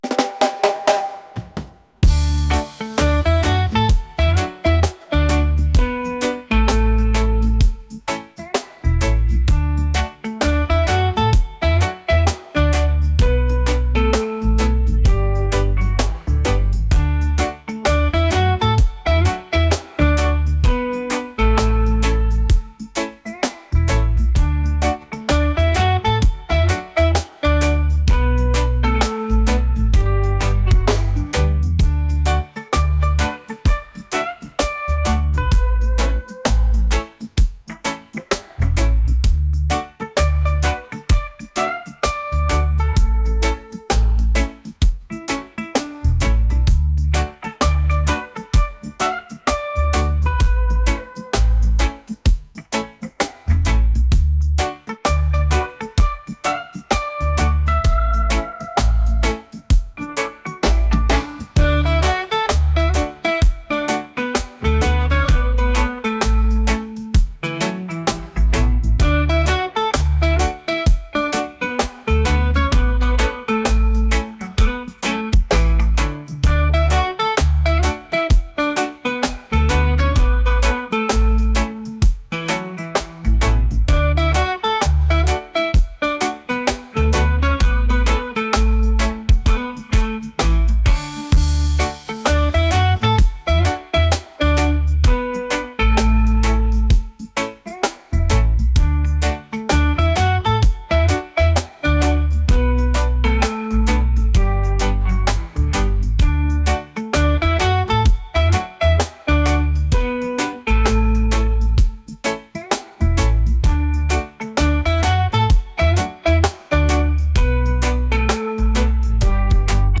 reggae | pop | soul & rnb